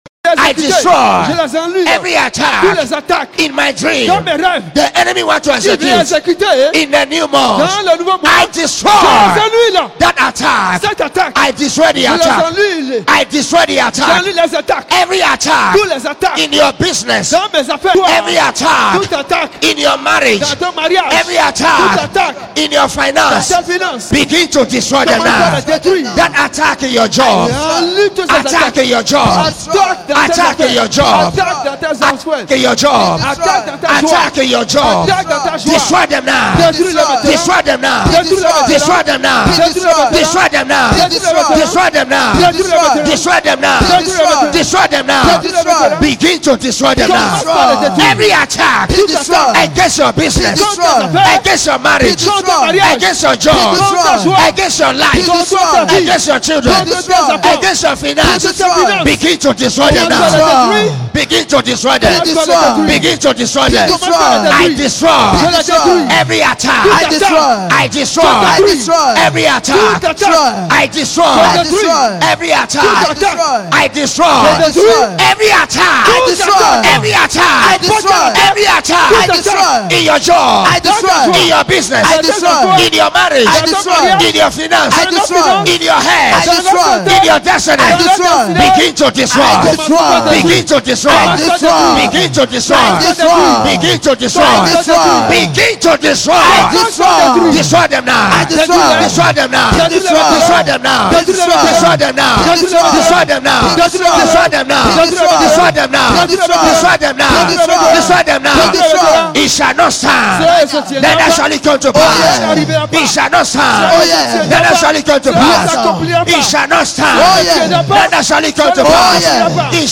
Prayer for the month.